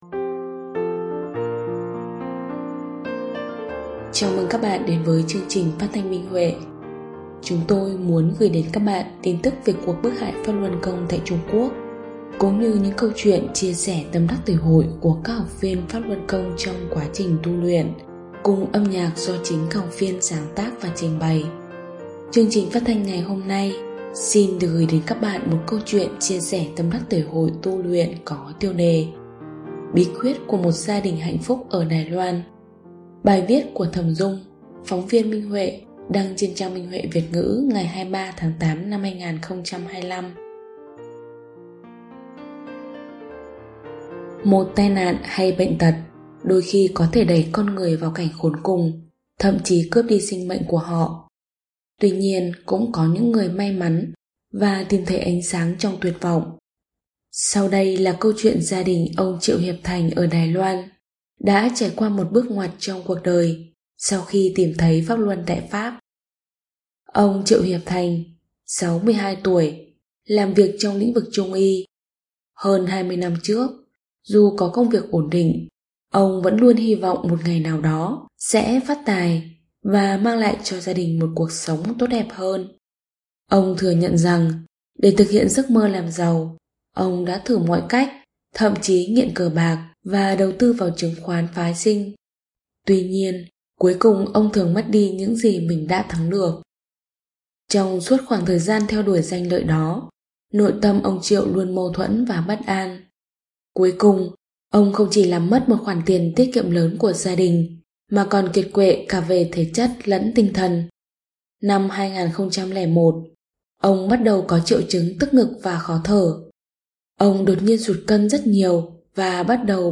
Chương trình phát thanh số 400: Tin tức Pháp Luân Đại Pháp trên thế giới – Ngày 10/11/2025